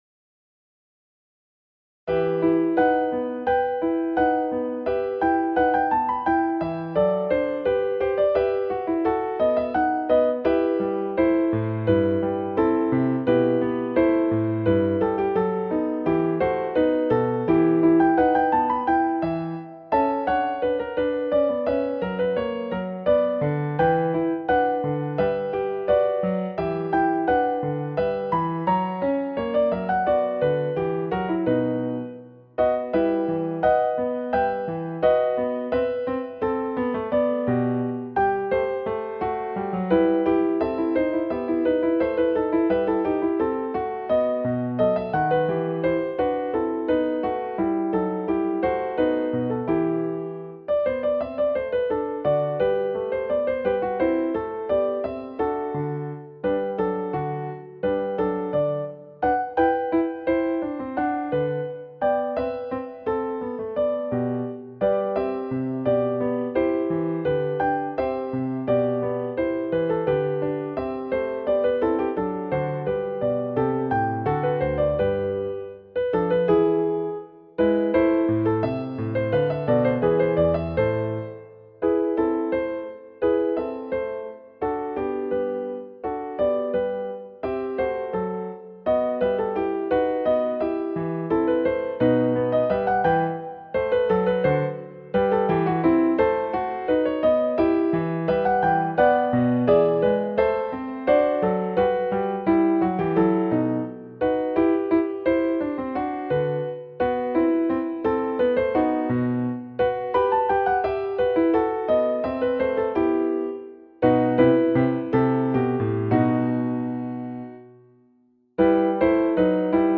Handel Allegro Backing Track